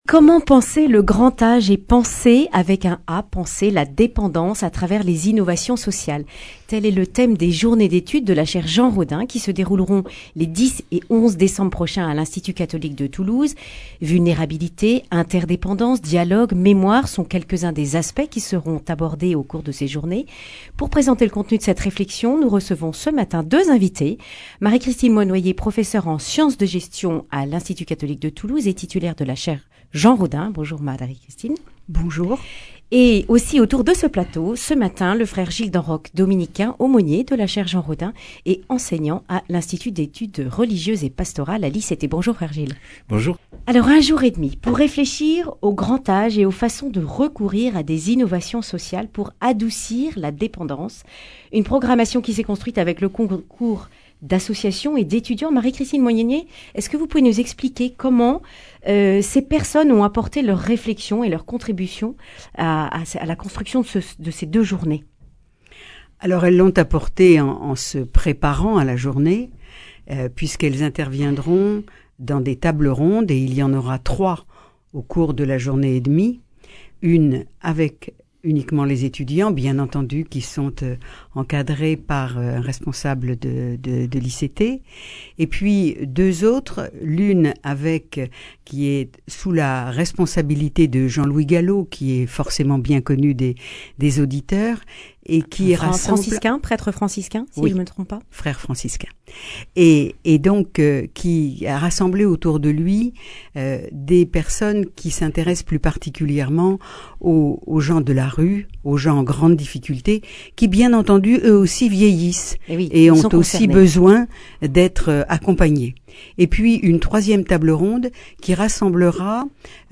Accueil \ Emissions \ Information \ Régionale \ Le grand entretien \ Comment penser le grand âge et panser la dépendance à travers les (…)